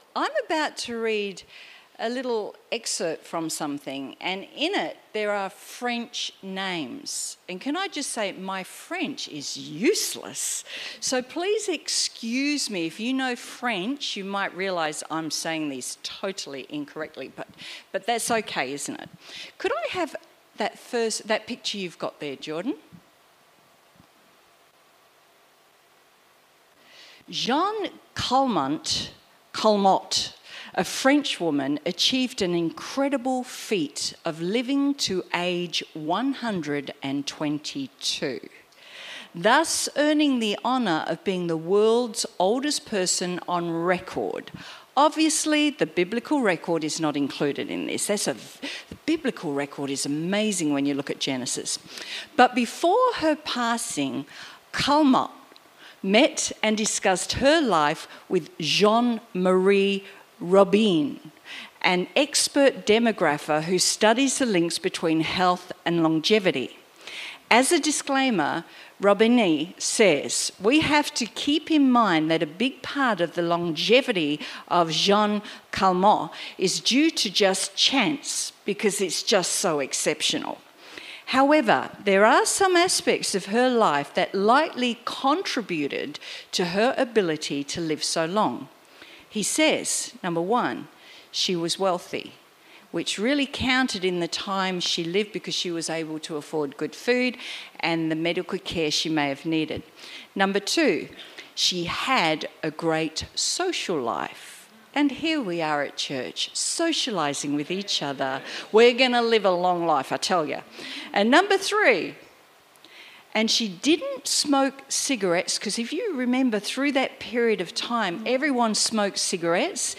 Sermon Transcript